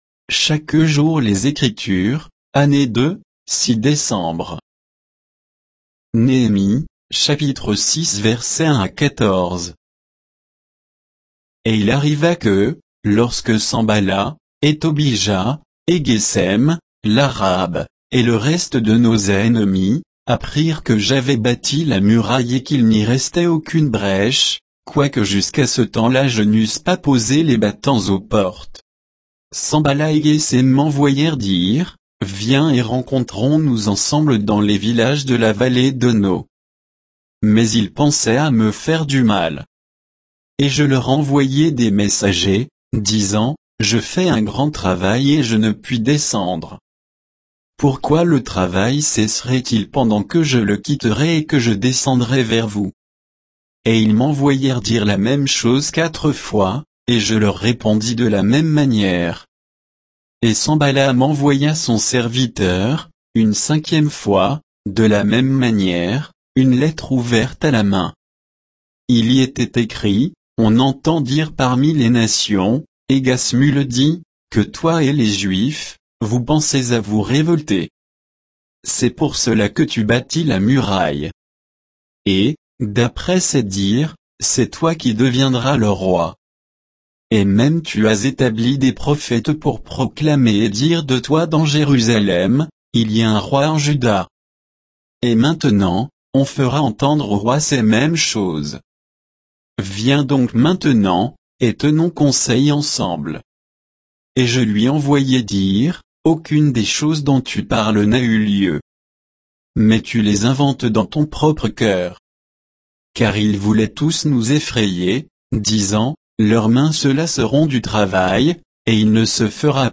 Méditation quoditienne de Chaque jour les Écritures sur Néhémie 6